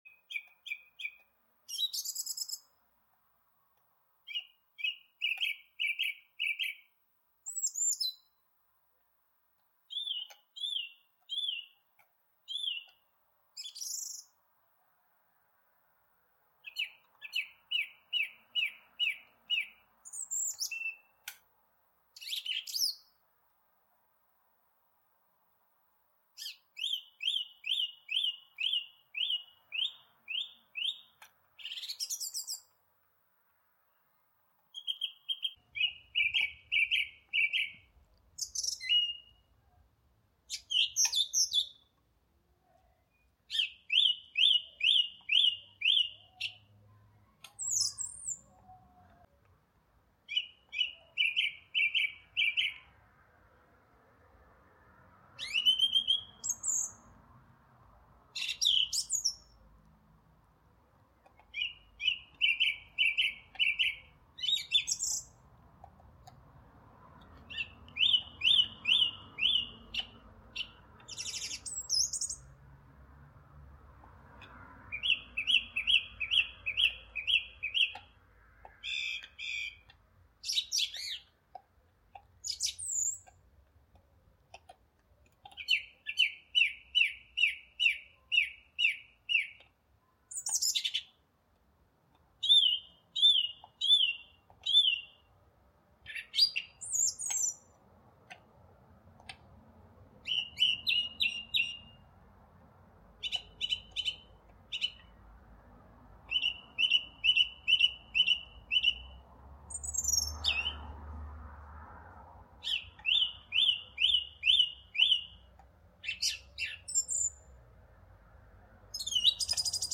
Bird Song Song Thrush sound effects free download
Bird Song - Song Thrush sings on a summer evening